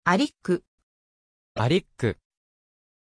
Pronuncia di Èric
pronunciation-èric-ja.mp3